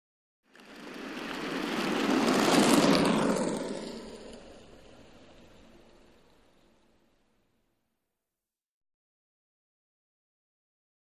Roller Skating; Coasting By.